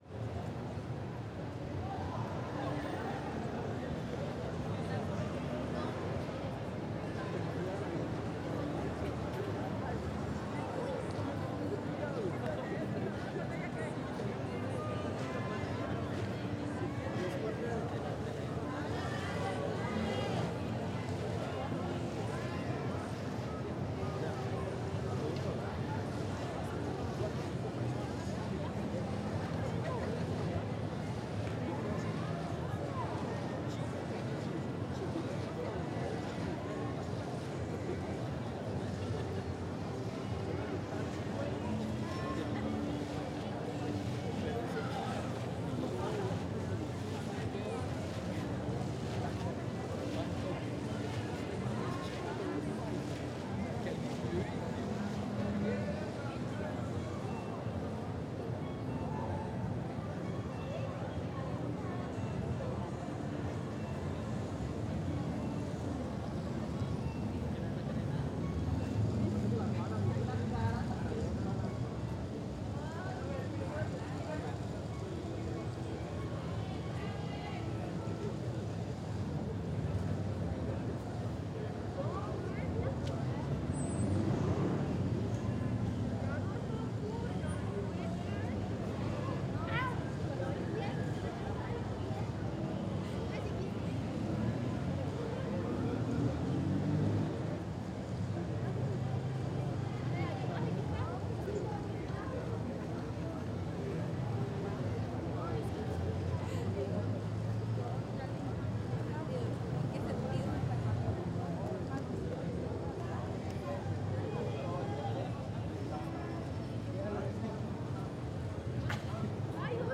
dc.description.abstractSe escucha actividad humana bastante dinámica y activa en la Plaza de la Cultura un miércoles por la noche.
En la avenida hay un tránsito peatonal bastante fluído y ventas informales. También hay un guitarrista que se distingue a lo lejos. Aún así, la grabación no detalla en ninguna voz, las conversaciones son efímeras y predominan las voces ininteligibles.es_ES
dcterms.audio.microphoneZoom H6 con la cápsula MS y Rode NTG3 con la caña y el blimp.
Bancas de la Plaza de la Cultura (Mono) noche ACM 2018.mp3